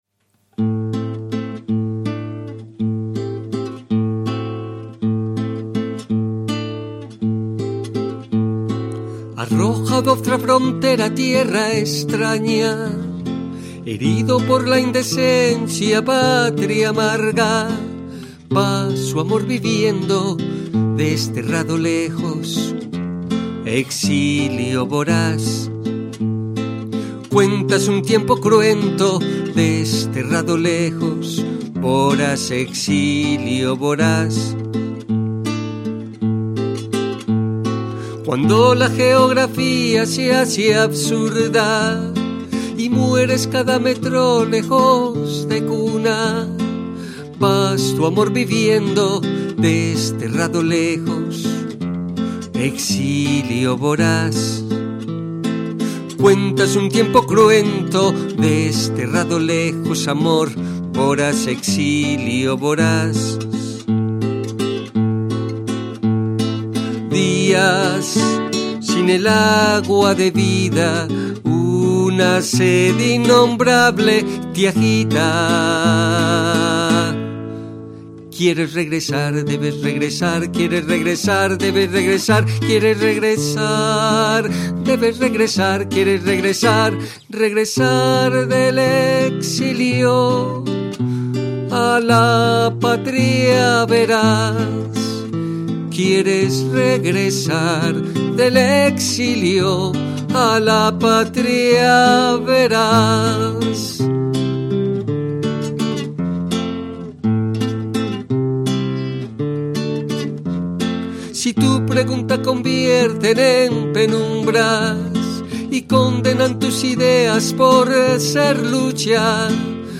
Canción
voz y guitarra.